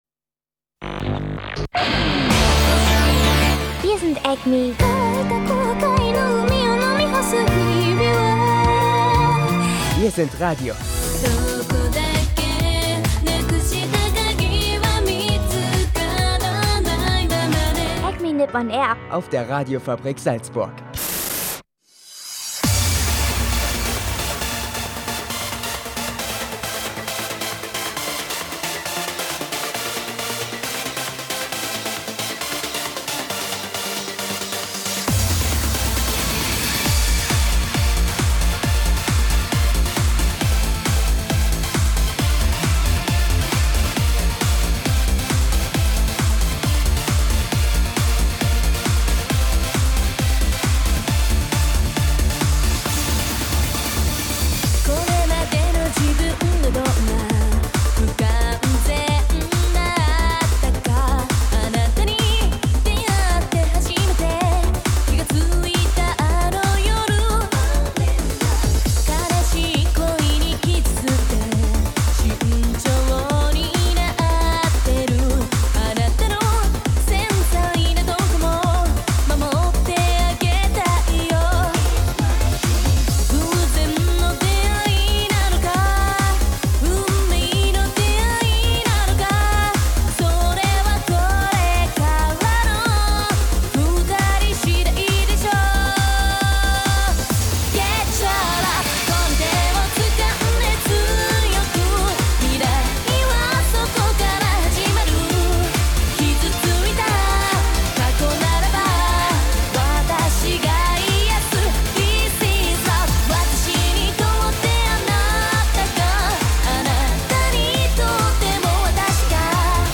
Diese Woche gibt es viel Musik aus Anime und Japan und dazu frische Animenews zu New Game, Toradora und Japan-News zu Trump, dem Tennou und Bemühungen in Japan, das JK-Business zu bändigen.